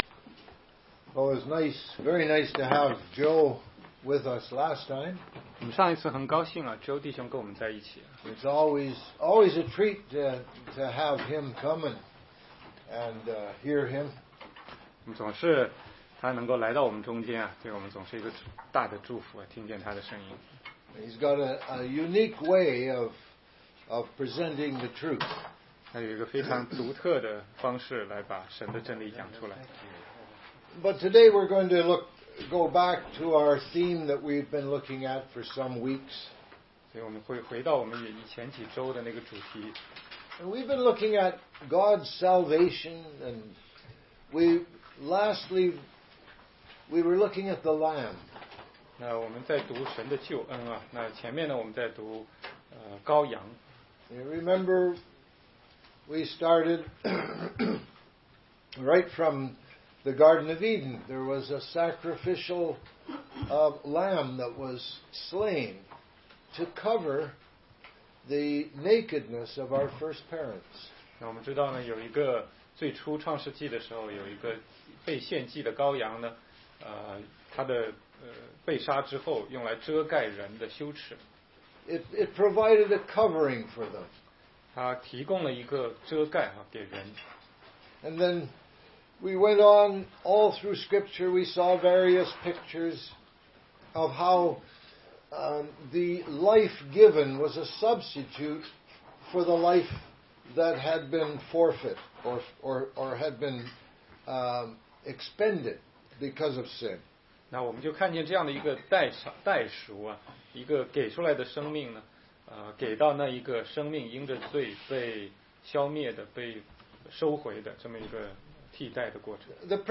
16街讲道录音 - 怎样才能读懂圣经系列之十四